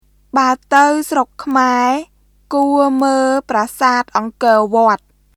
[バウ・タウ・スロック・クマエ・クオ・ムール・プラサート・オンコー・ヴォアット　baə tə̀w srok kʰmae kuˑo mə̀ːl praːsaːt ʔɔŋkɔ̀ː vɔət]